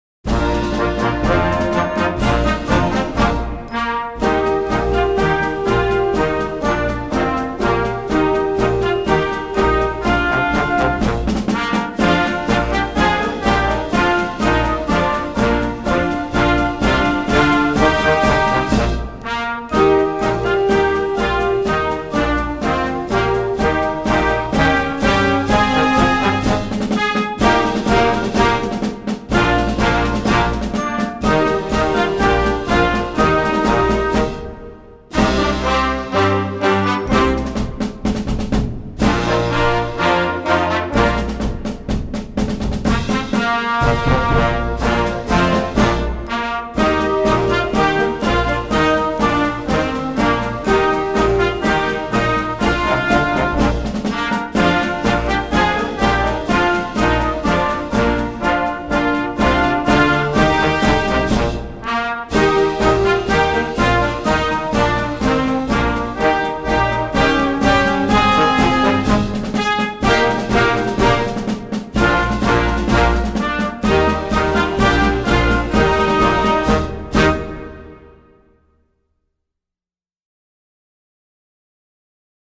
Voicing: Flex March